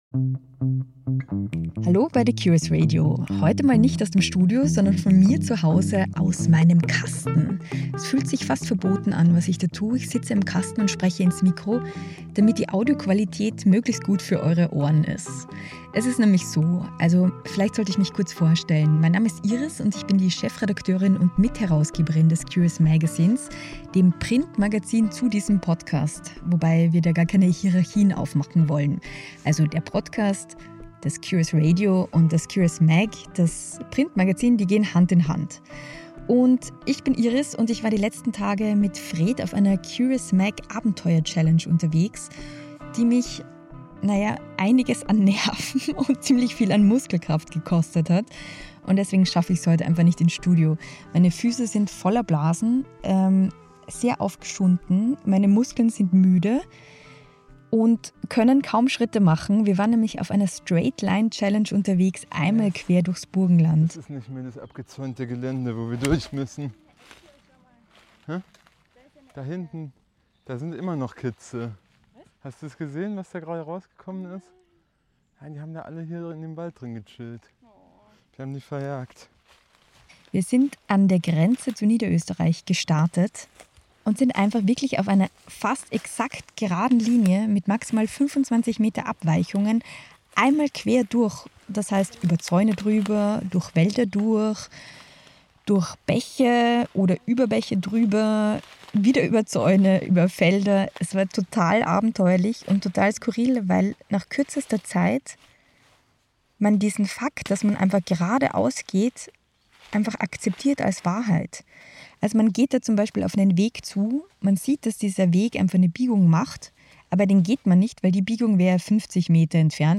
Audioessay